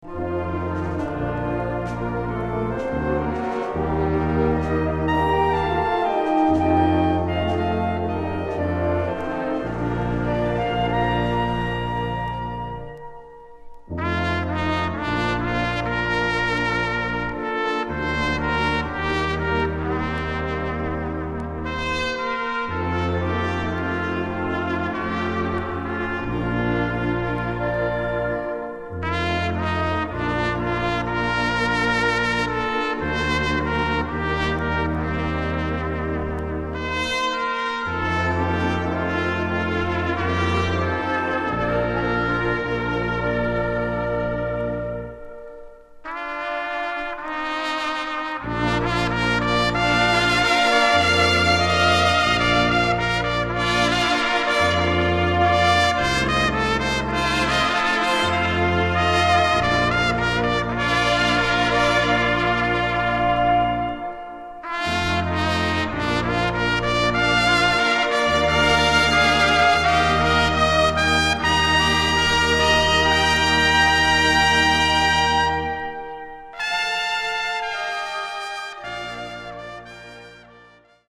einem Bravourstück für zwei Trompeten